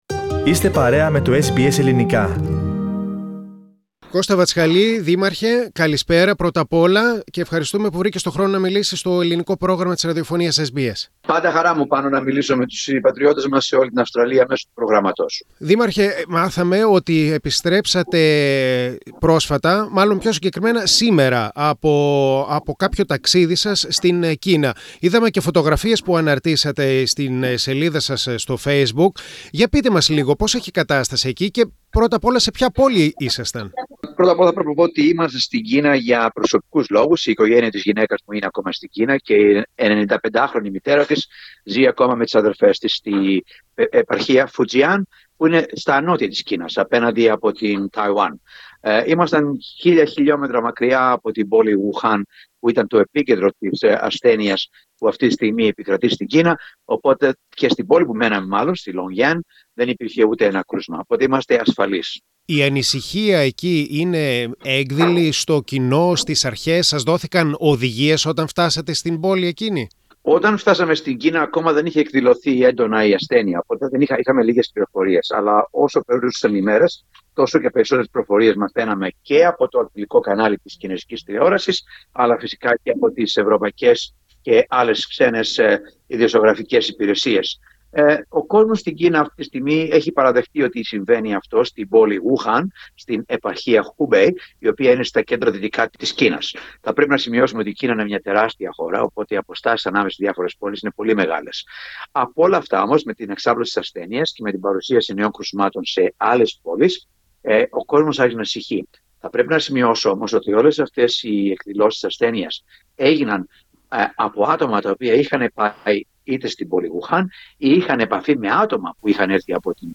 Στην Κίνα όπου εξαπλώνεται με γρήγορους ρυθμούς ο κοροναϊός βρέθηκε ο Ελληνικής καταγωγής Δήμαρχος της πρωτεύουσας της Βόρειας Επικράτειας της Αυστραλίας, Ντάργουϊν (Darwin) Κώστας Βατσκαλής και μίλησε στο Πρόγραμμα SBS Greek.